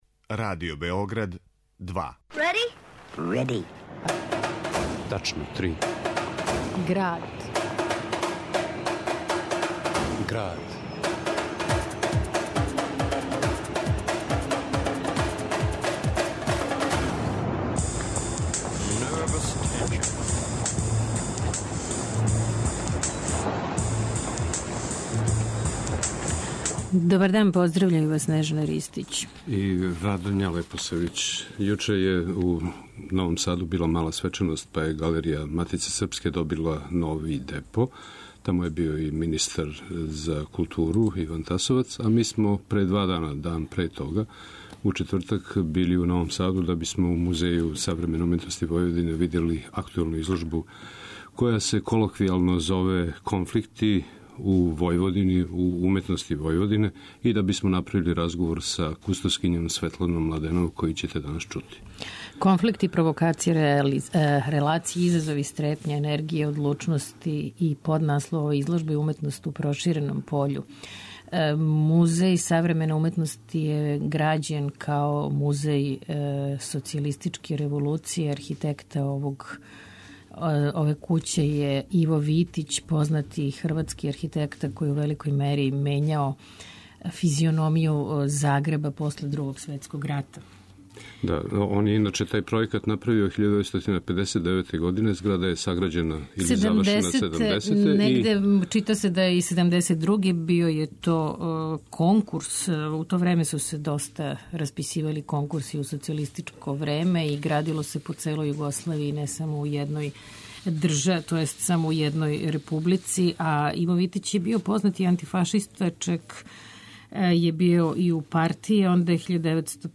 У Граду , уз бројне аудио инсерте уметничких радова